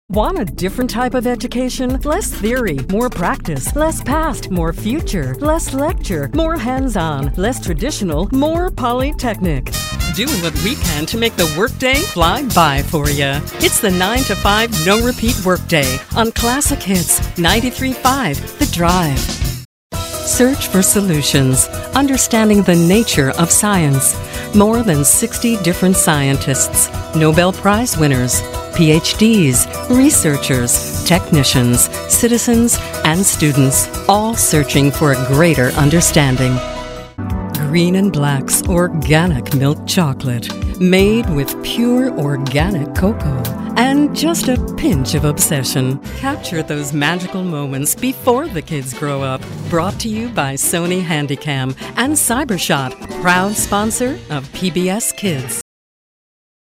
Women Voice Over Talent - The Diamond Agency | Orlando's Most Premier Talent Agency
Diamond-Agency-Commercial-VO-Reel.mp3